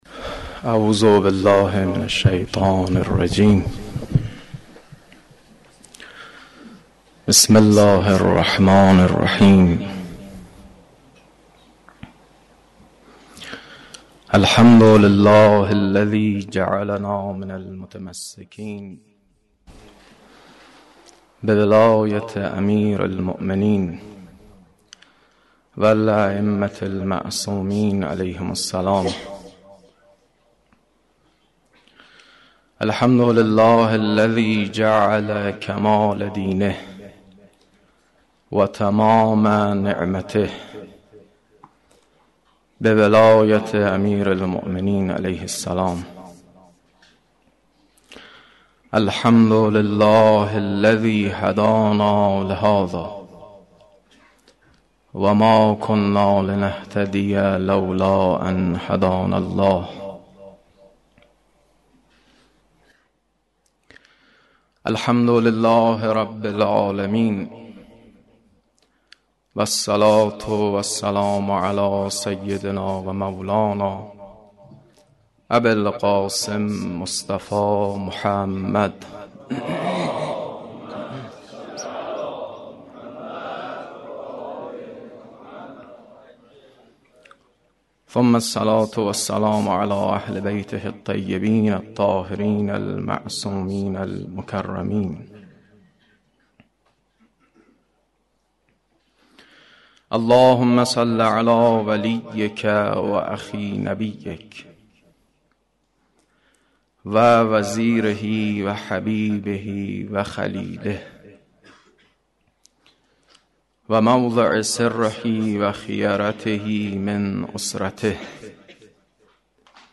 مدیحه خوانی در عید غدیر سال 1433 هـ.ق